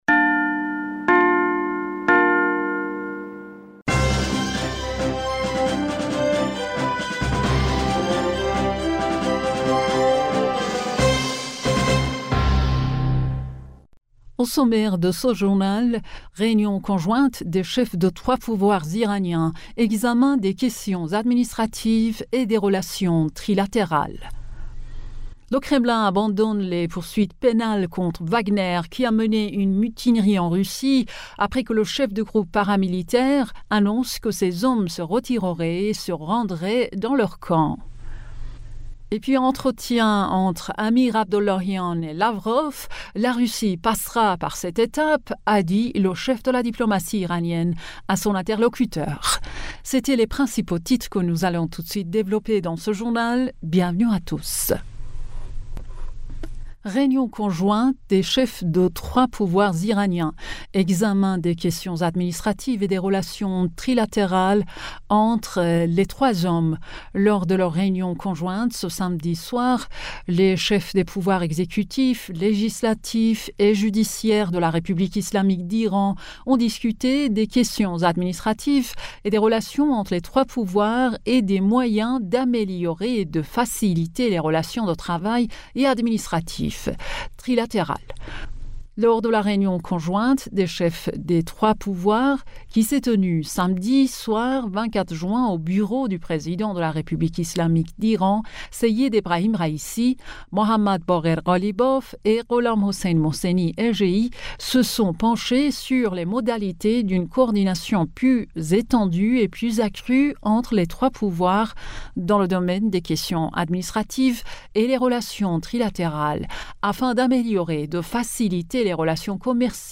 Bulletin d'information du 25 Juin 2023